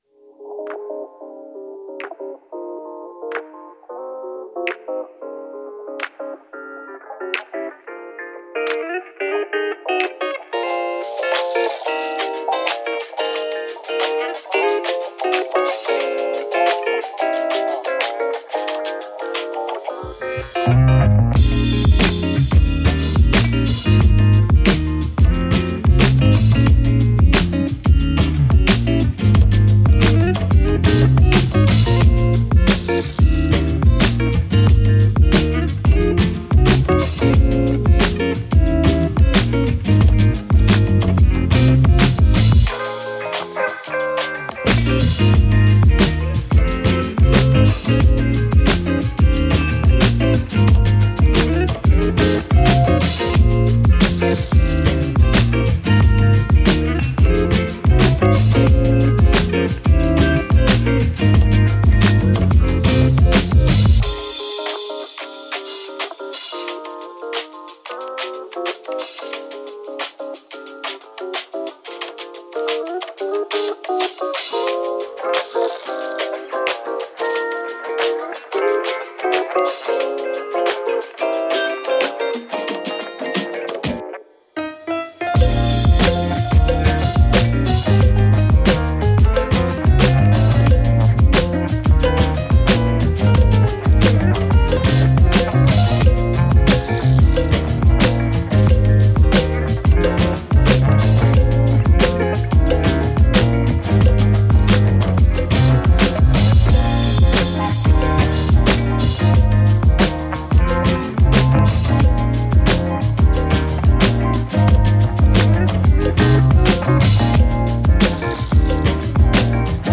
Музыка для хорошего настроения